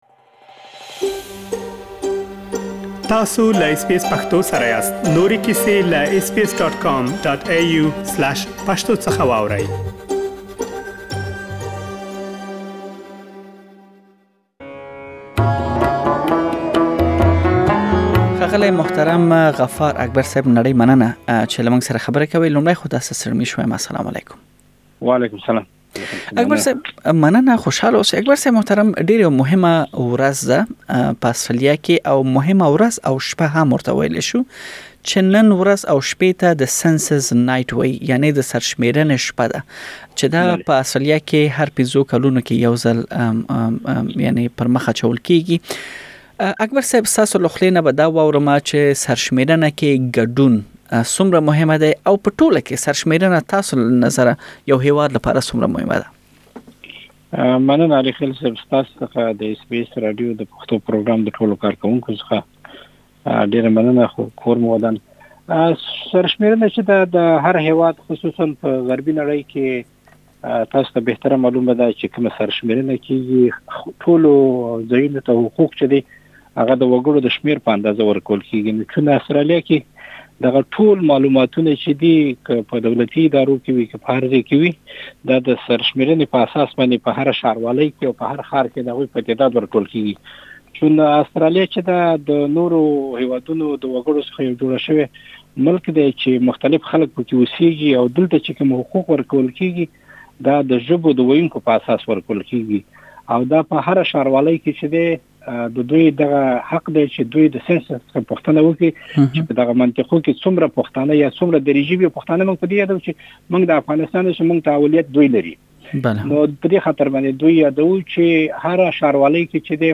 مرکې کړي چې تاسې د هغوی خبرې دلته اوريدلی شئ.